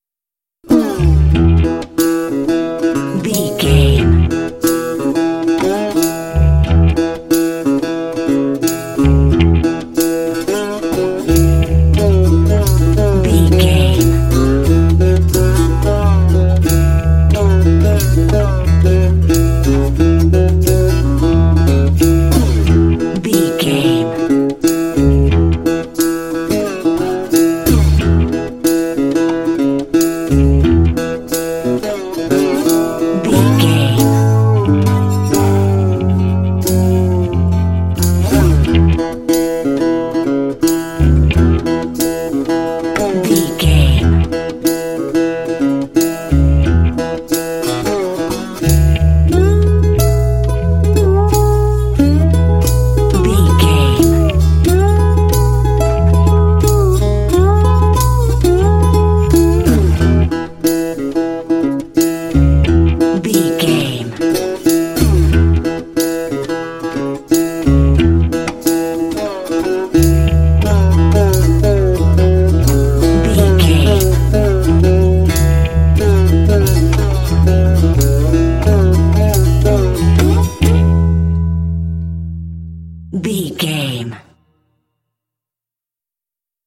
Uplifting
Mixolydian
playful
joyful
acoustic guitar
percussion
bass guitar
country
bluegrass